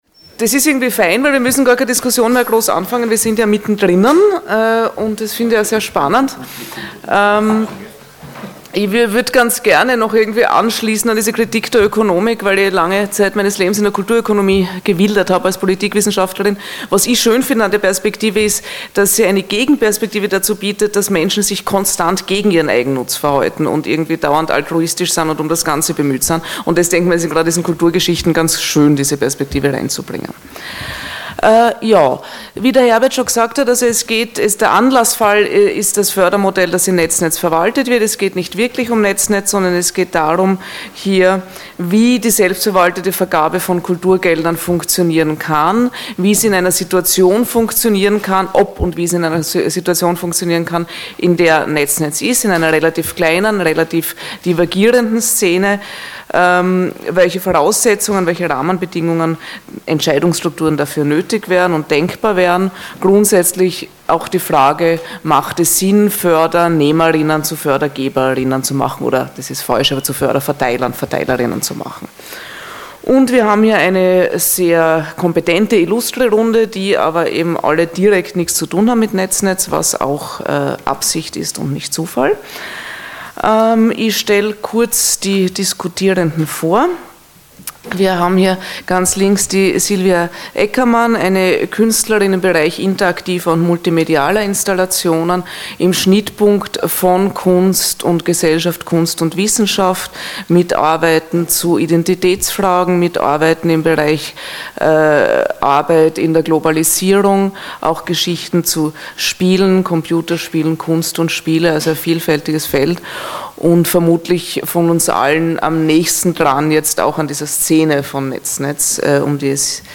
Die Diskussion
diskussion_nur_ton_96kbps.mp3